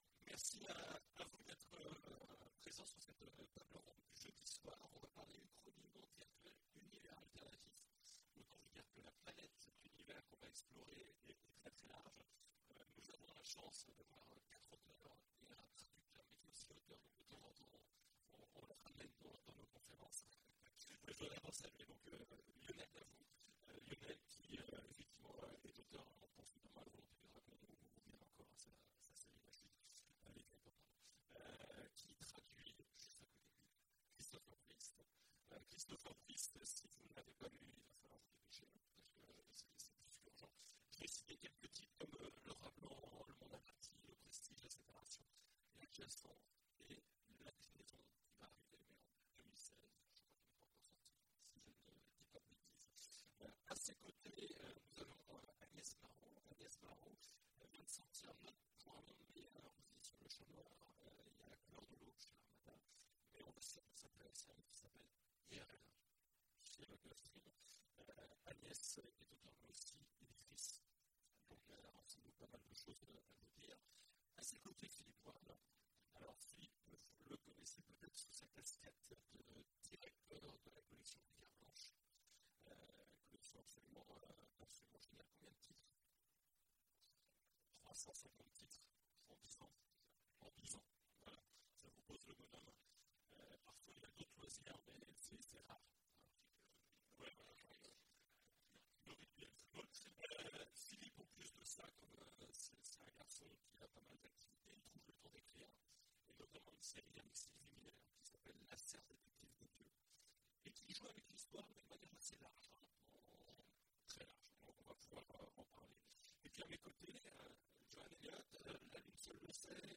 Imaginales 2016 : Conférence Uchronies, mondes virtuels…